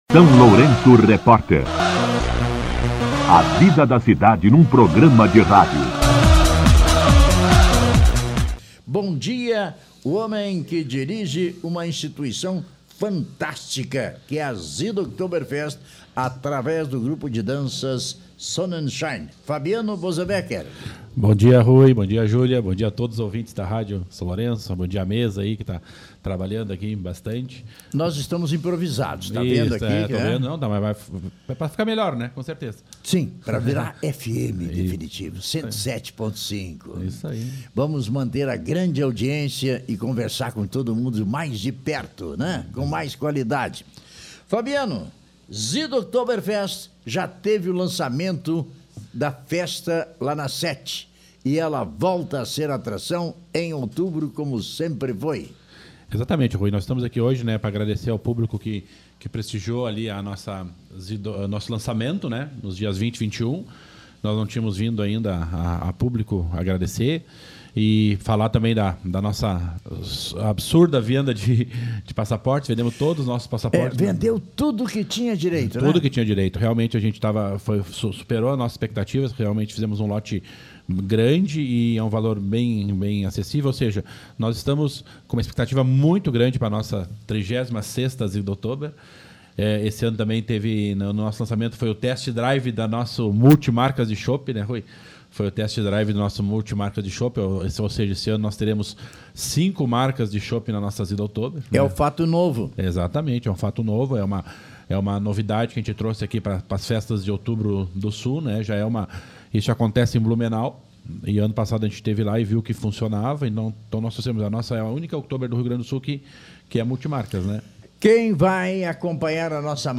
esteve na manhã desta sexta-feira (1º) no SLR RÁDIO para divulgar a abertura do segundo lote de passaportes para a 36ª Südoktoberfest. As vendas iniciam na próxima segunda-feira, 4 de agosto, na Loja Monjuá, ao valor de R$ 120,00.